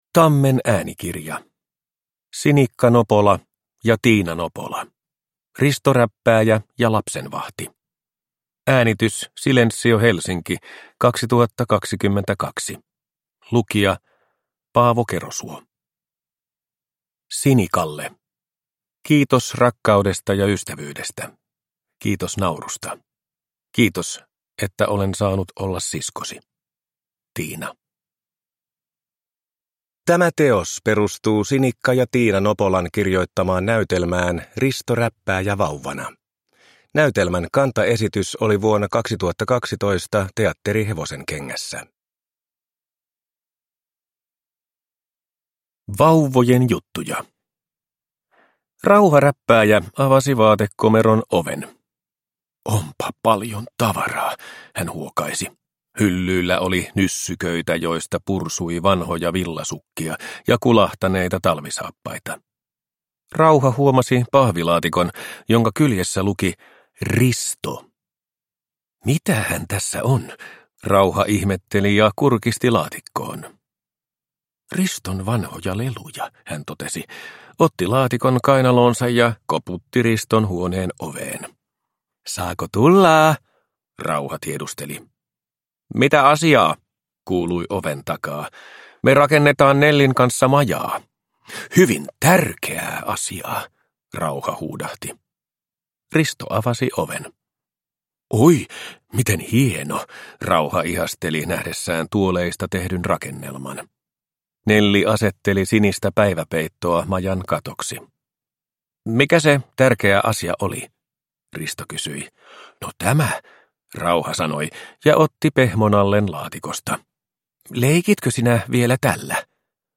Risto Räppääjä ja lapsenvahti – Ljudbok – Laddas ner